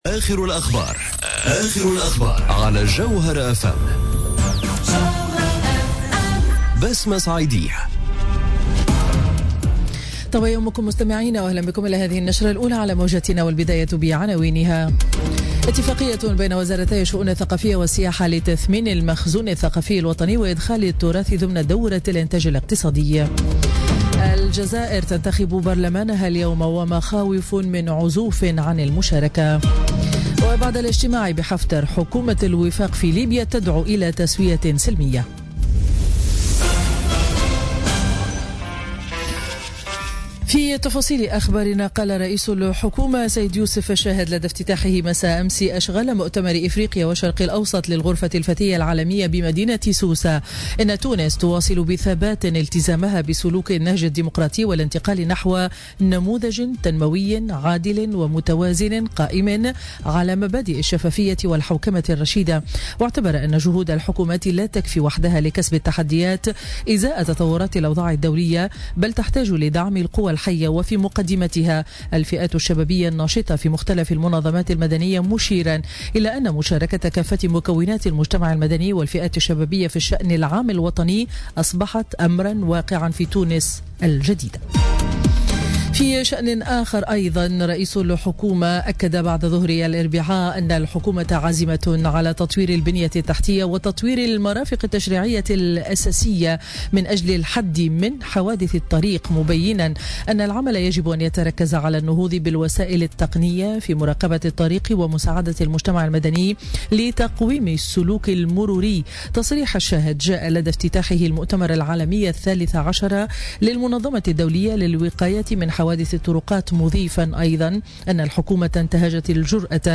نشرة أخبار السابعة صباحا ليوم الخميس 4 ماي 2017